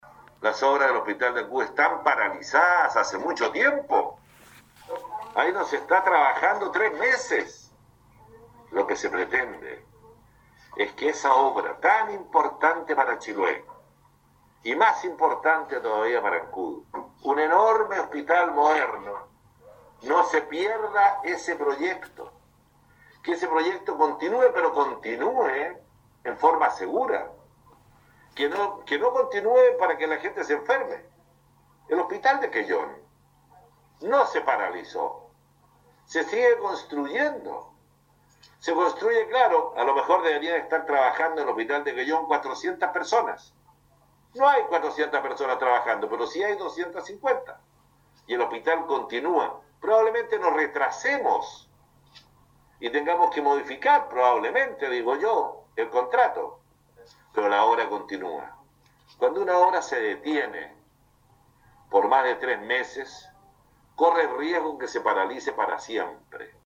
Sobre la medida que instruyó el ministro de Salud Enrique Paris, de mantener la actual suspensión de los trabajos de construcción del nuevo Hospital de Ancud, se refirió el intendente de Los Lagos.